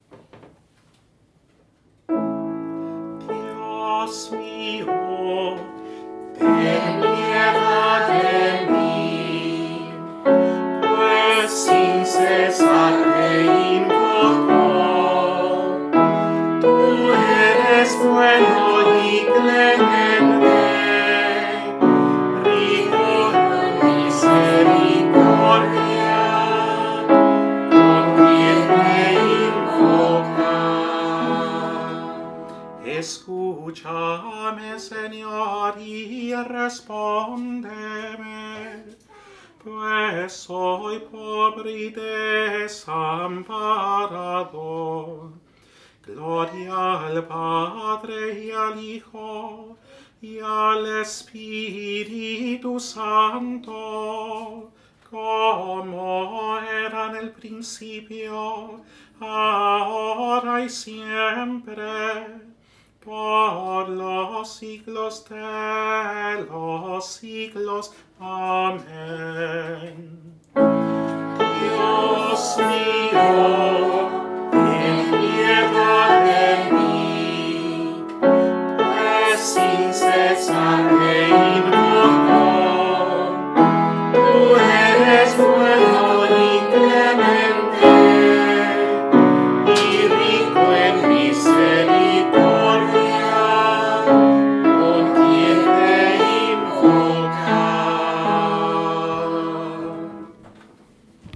choir at Holy Cross Catholic Church, Kernersville, NC.
Antifona-de-Entrada-XXII-TO-Coro.mp3